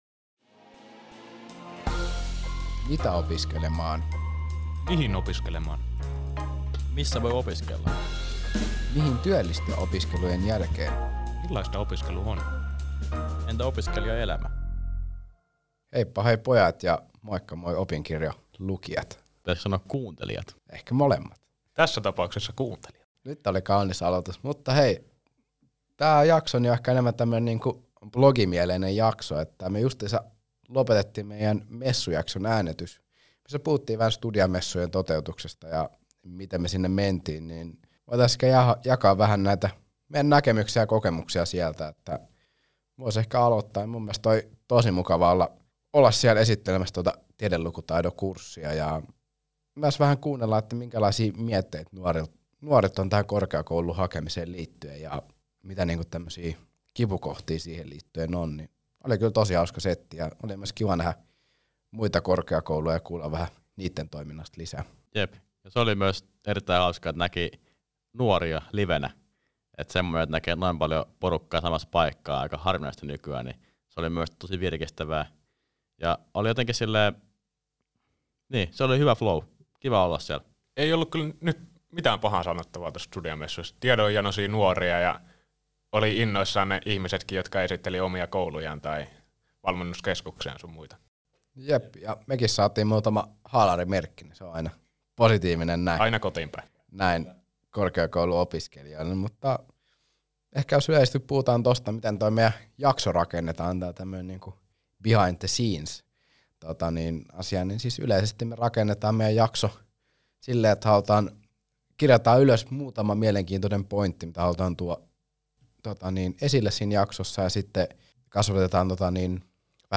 Tässä ääniblogissa Opiskelemaan-podcastin nelikko kertoo Opinkirjon sivuston lukijoille  kokemuksistaan Studia-messuilta ja siitä, kuinka uusi podcast-jakso syntyi. Messukohtaamisissaan Opiskelemaan-porukka havaitsi, että nuoria mietityttää erityisesti korkeakouluopintojen haastavuus ja ajankäyttö sekä oman porukan ja kaverien löytäminen.